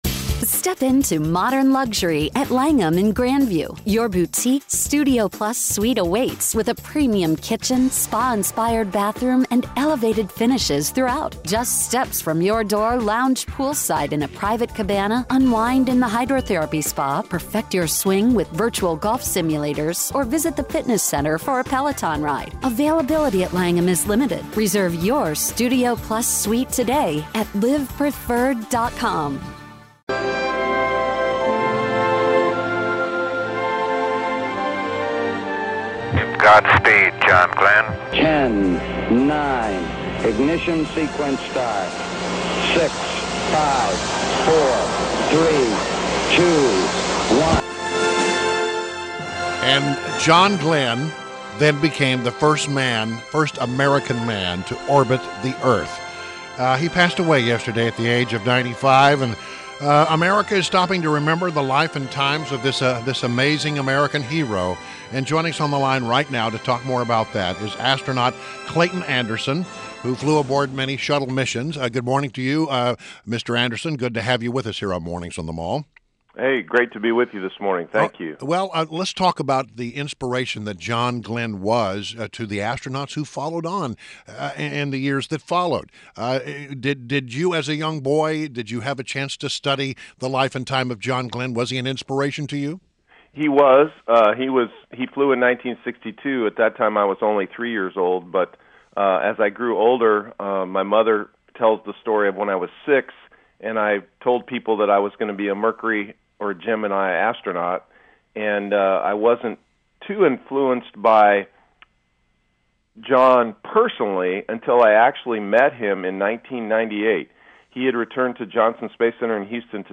INTERVIEW — Astronaut CLAYTON ANDERSON